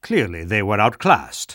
Spy_cheers08.wav